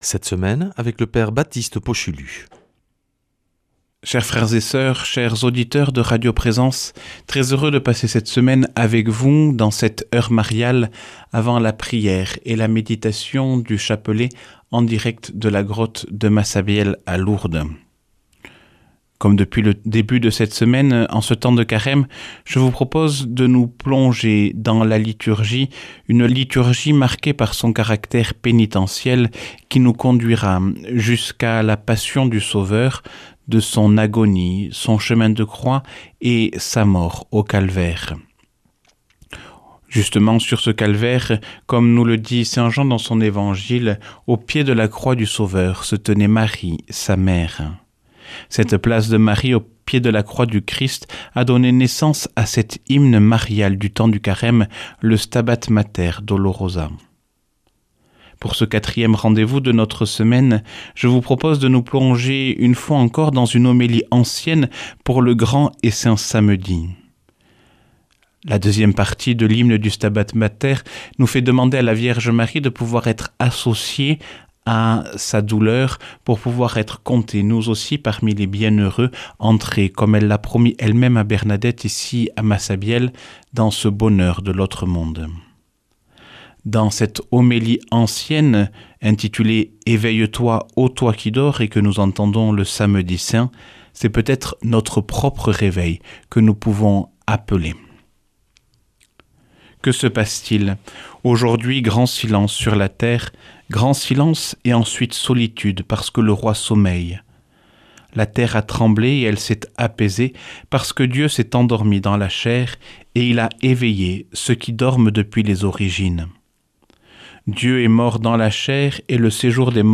15H00 | Enseignement Marial